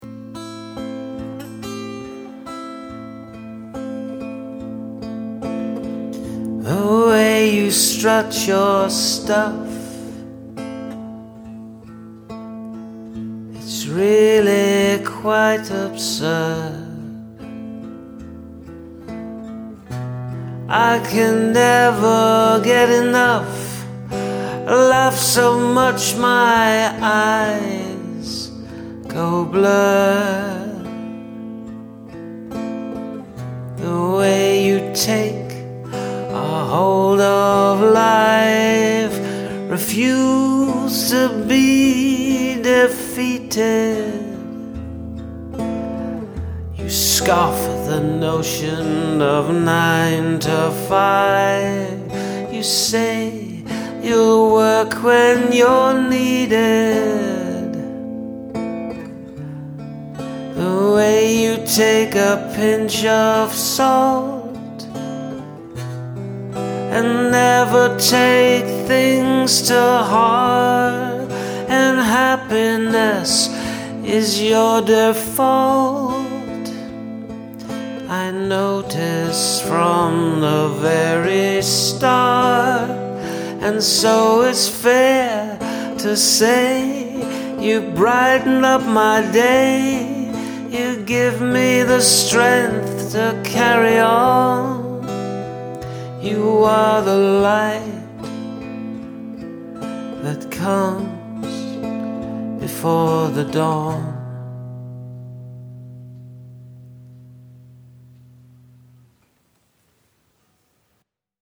Skirmish: Sad song in major key; ha...
Happy lyrics, fairly downer music (minor)